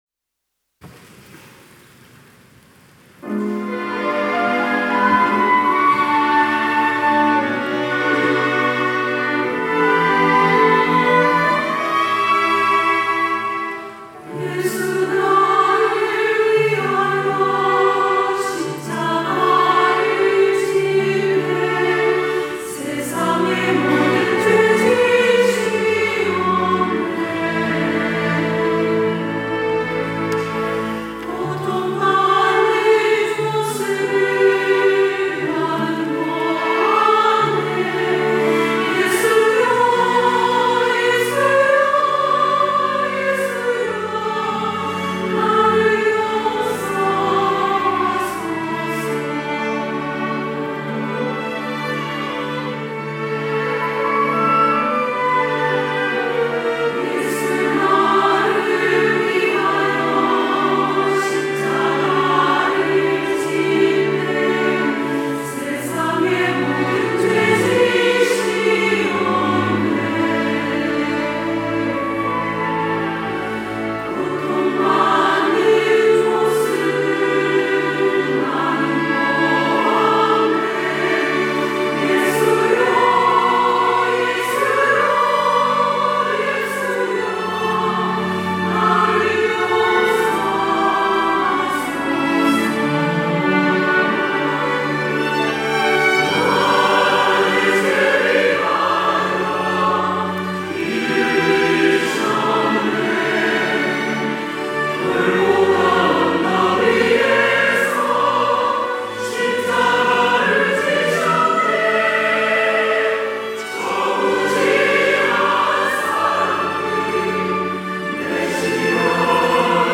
호산나(주일3부) - 예수 나를 위하여
찬양대